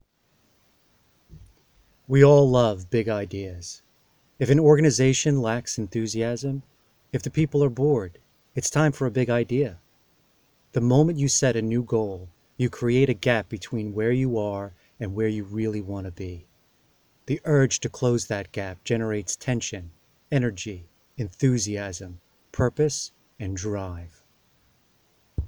VOICE REEL
American Actor, highly proficient in German.